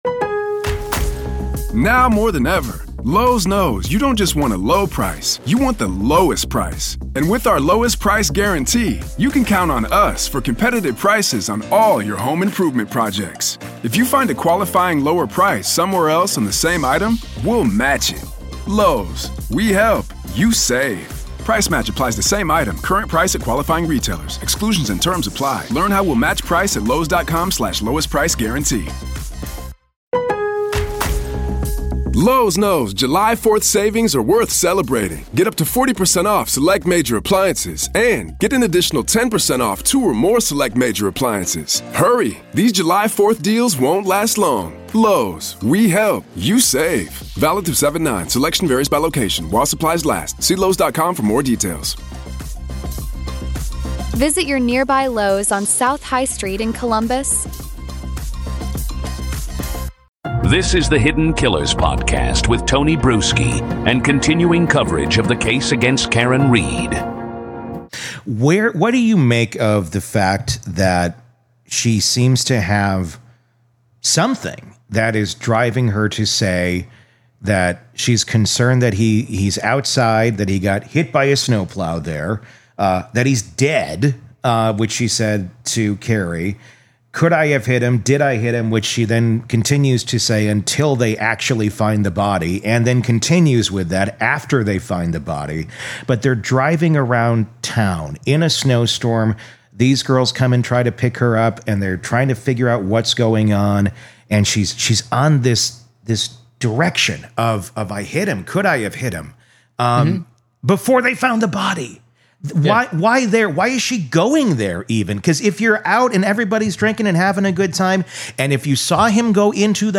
The Question That Could Break the Karen Read Case 'Did I Hit Him', FBI Behavior Expert Talks